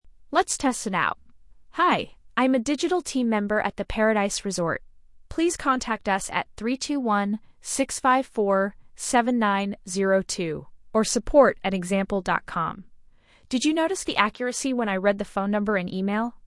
Premium Voices suitable for real-time streaming.
feminine, clear, confident, energetic, enthusiastic